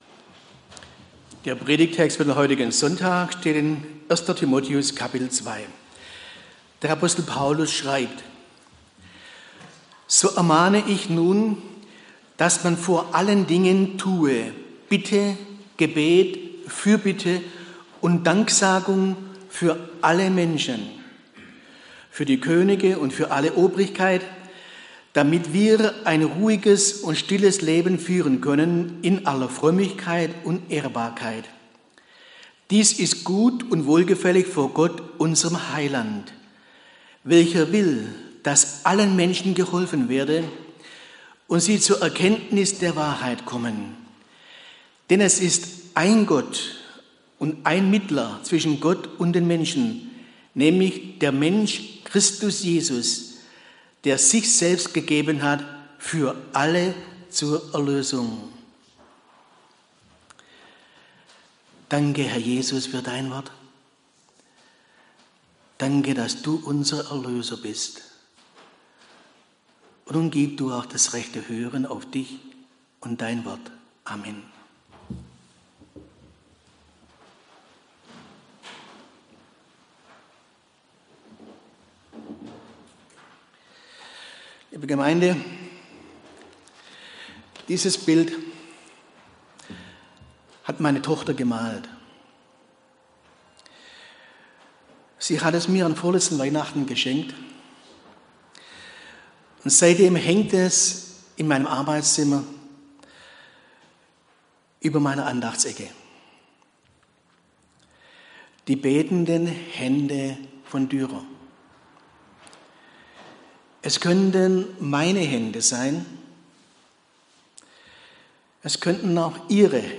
Predigt am Sonntag Rogate: Betet! (1. Tim 2,1-6)
predigt-am-sonntag-rogate-betet-1-tim-21-6